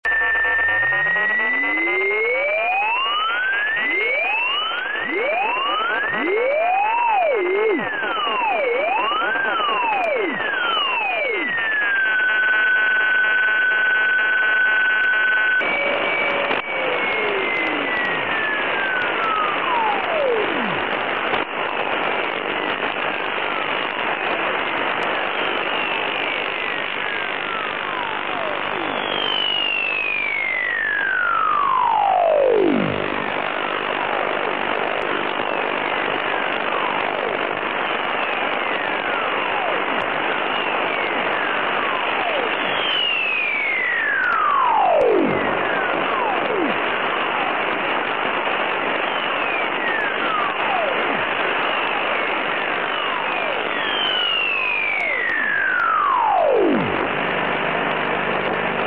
Sound of interference tuning 0 - 50kHz of 432 MHz band